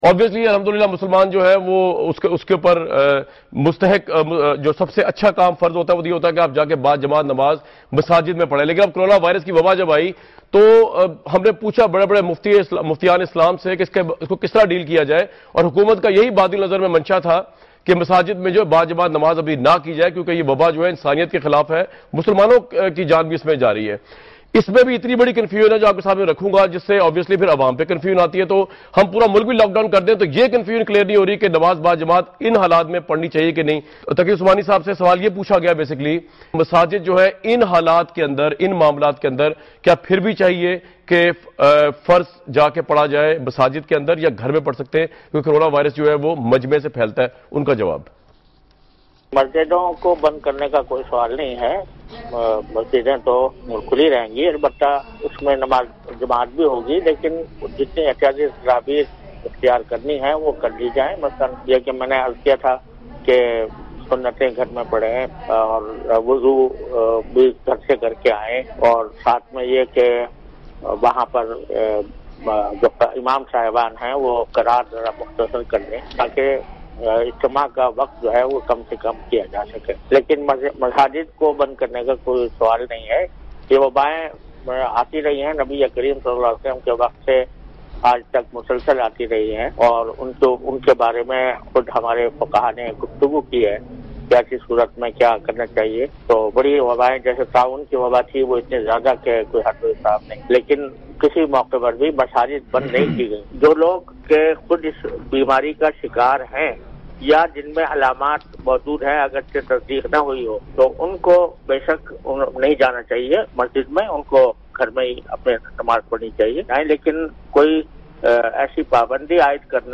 Category: TV Programs / Dunya News / Questions_Answers /
جناب جاوی احمد غامدی کی دنیا ٹی وی کے پروگرام “آن دی فرنٹ” میں کی گئی گفتگو ۔